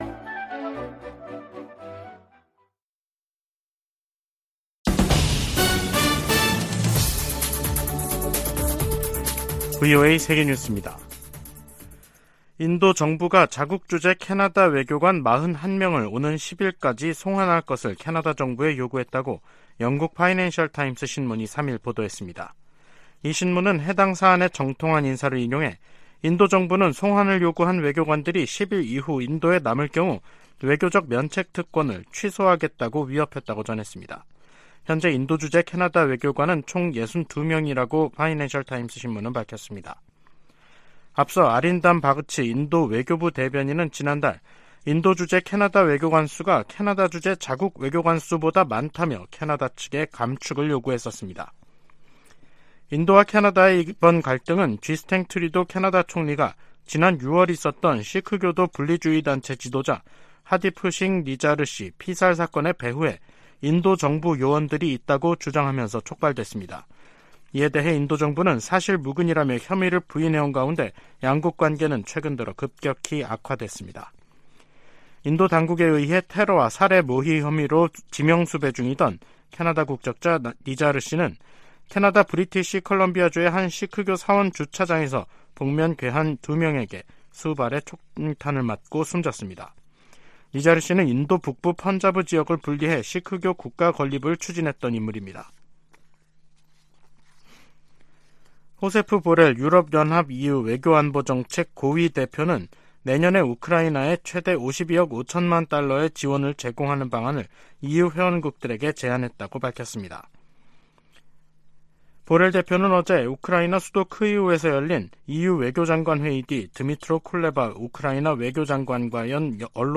세계 뉴스와 함께 미국의 모든 것을 소개하는 '생방송 여기는 워싱턴입니다', 2023년 10월 3일 저녁 방송입니다. '지구촌 오늘'에서는 유럽연합(EU) 외교장관들이 우크라이나에 지속적 지원을 다짐한 소식 전해드리고, '아메리카 나우'에서는 공화당 강경파가 정부 '셧다운'을 피하기 위해 단기 임시 예산안 통과를 주도한 같은 당 케빈 매카시 하원의장 해임 작업을 공식화한 이야기 살펴보겠습니다.